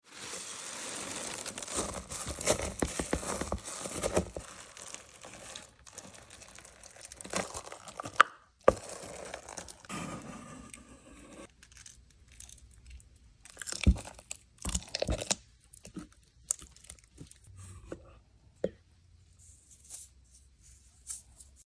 wax bean melting asmr wax 21 seconds 0 Downloads This is my job.